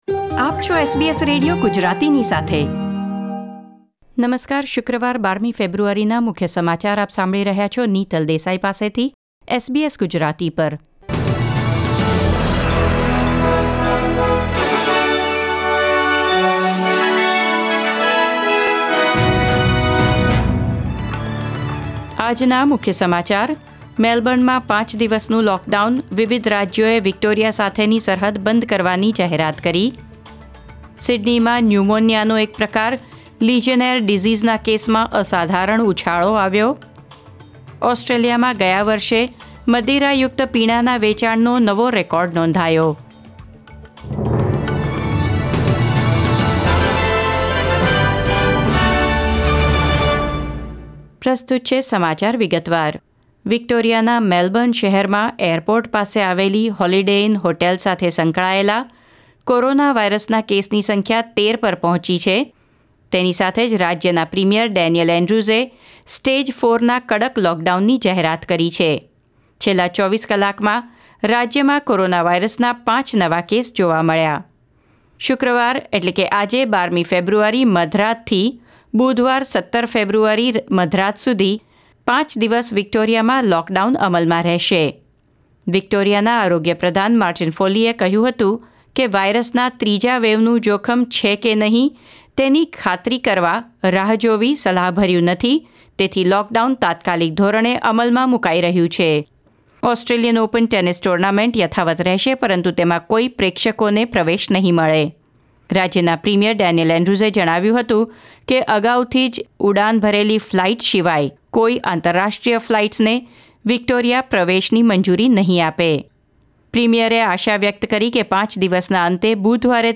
SBS Gujarati News Bulletin 12 February 2021